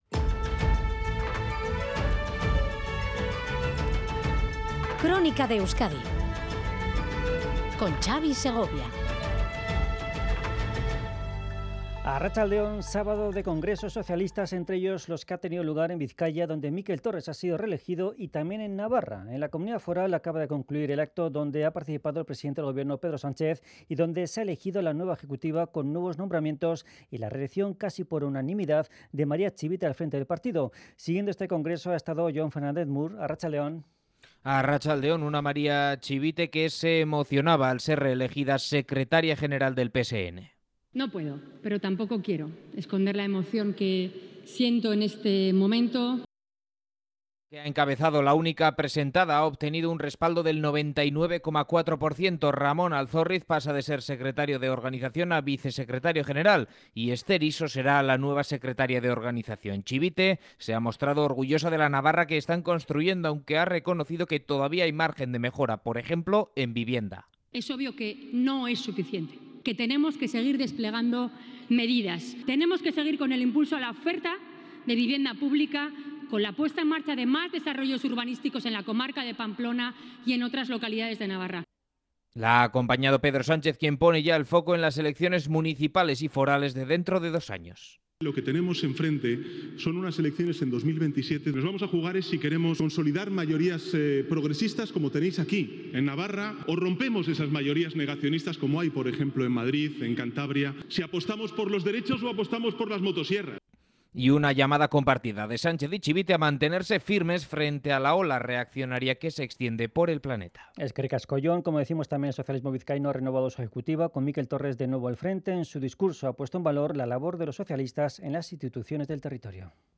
… continue reading 99 tập # Sociedad # Fin De Semana # Radio Euskadi (EITB # Países Bajos Noticias